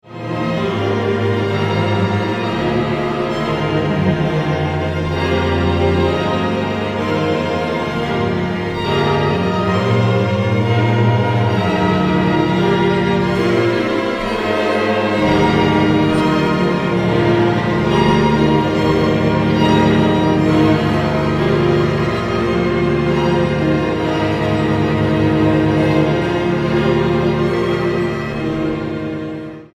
für Streichorchester
Beschreibung:Klassik; Orchestermusik; Ensemblemusik
Besetzung:Streichorchester
Uraufführung
Vestry Hall, Ealing, London